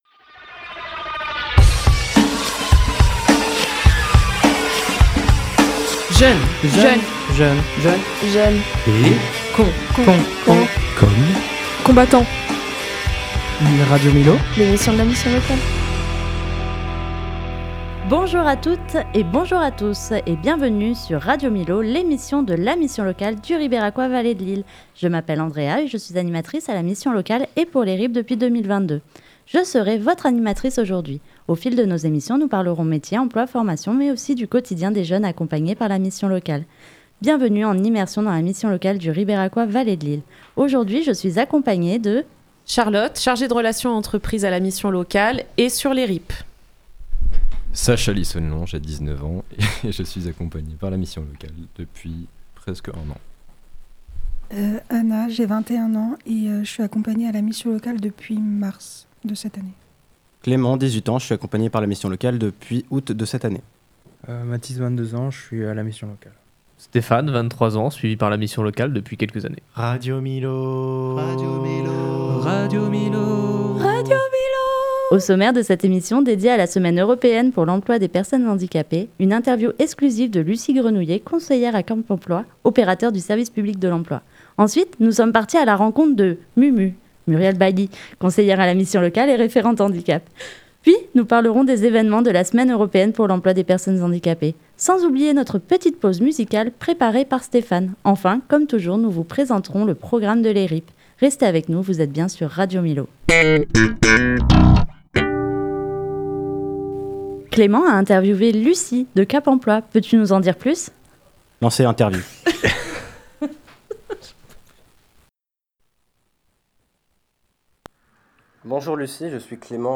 La mission locale et des jeunes prennent le micro.